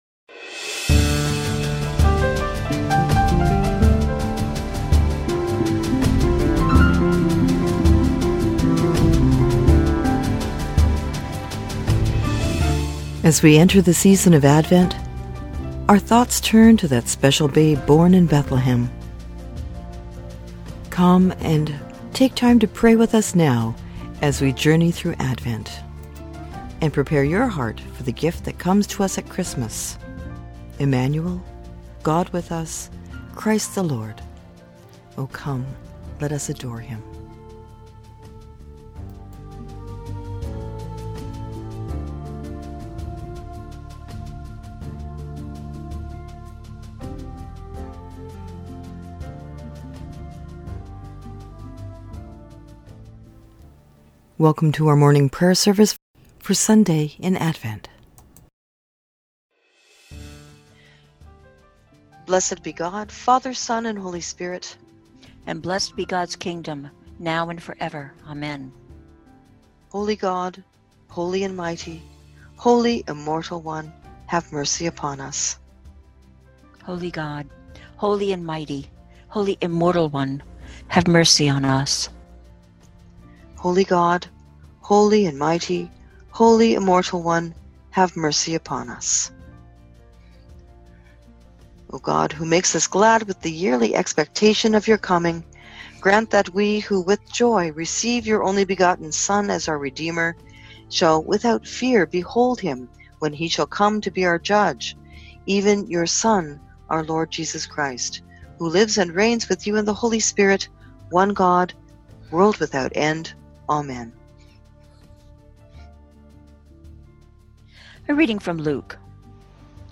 The Prayer Service for the Third Sunday in Advent